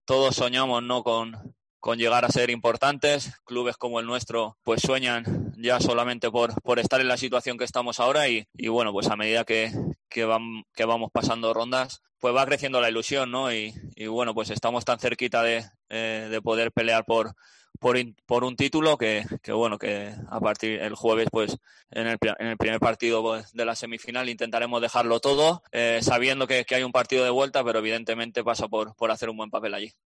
Estamos tan cerquita de poder pelear por un título que el jueves intentaremos dejarlo todo”, dijo Morales en la rueda de prensa posterior al entrenamiento.